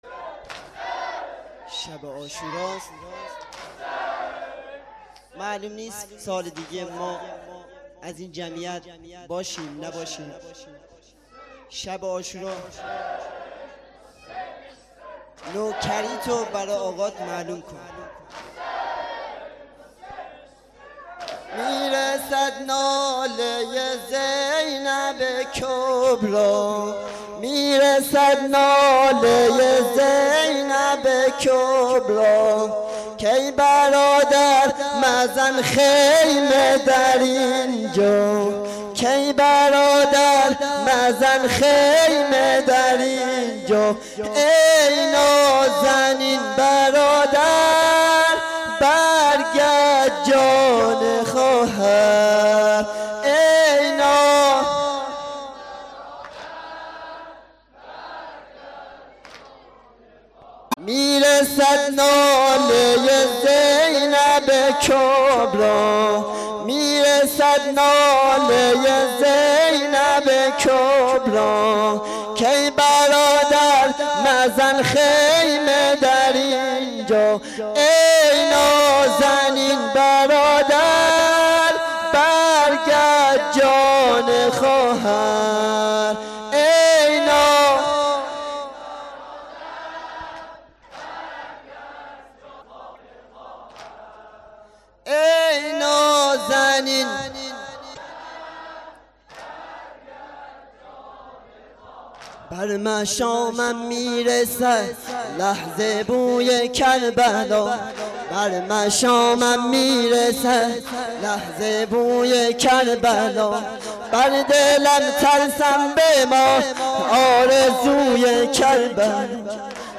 سینه زنی شور
• شب10 محرم93 هیئت ثارالله علیه السلام - سینه زنی شور.mp3